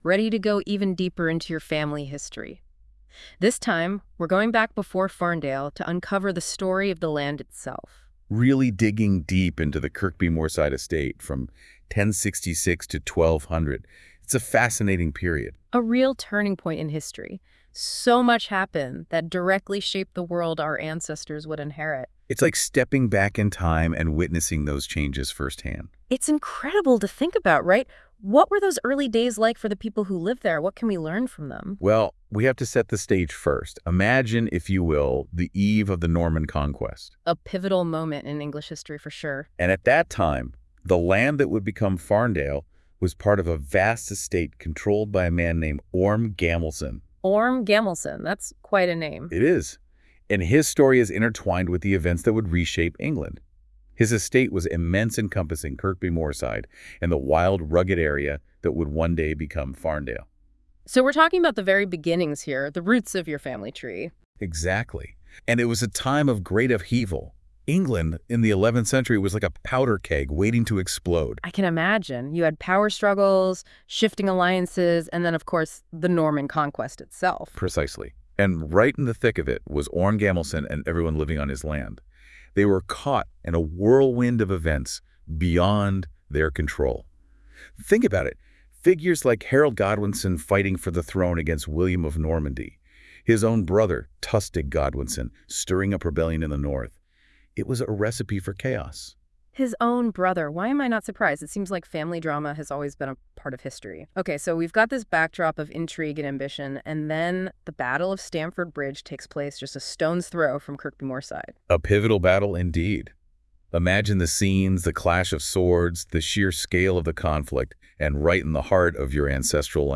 Using Google�s Notebook LM, listen to an AI powered podcast summarising this page.